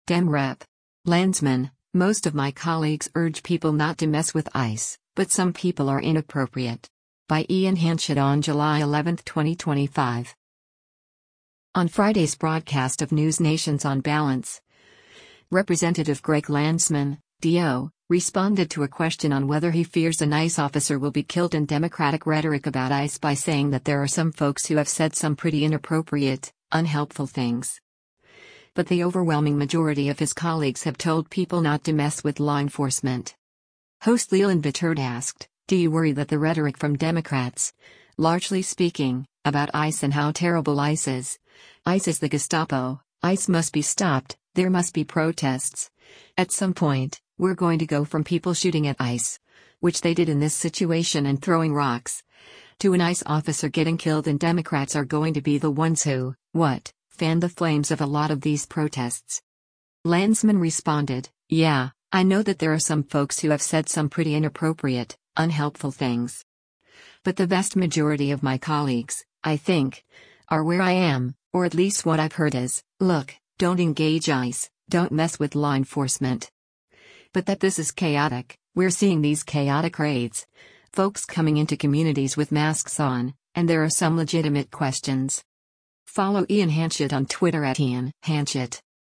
On Friday’s broadcast of NewsNation’s “On Balance,” Rep. Greg Landsman (D-OH) responded to a question on whether he fears an ICE officer will be killed and Democratic rhetoric about ICE by saying that “there are some folks who have said some pretty inappropriate, unhelpful things.” But the overwhelming majority of his colleagues have told people not to mess with law enforcement.